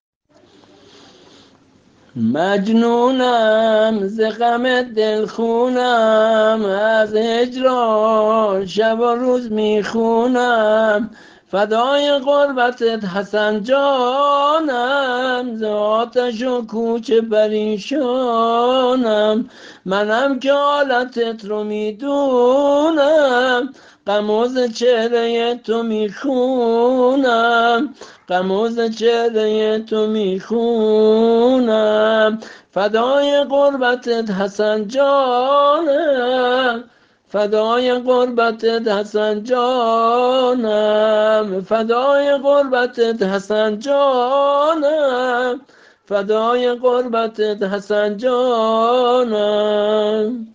◾زمزمه نوحه